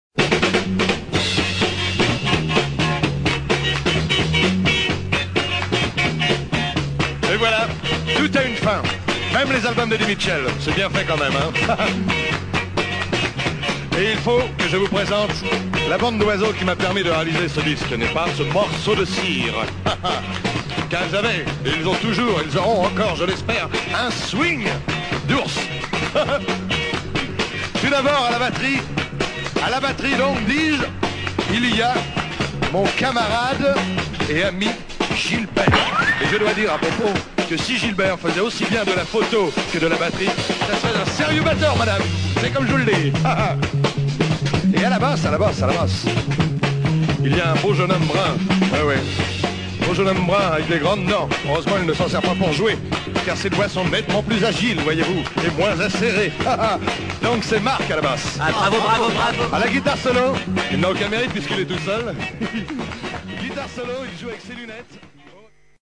and very interesting French soul & groovy pop. 7colts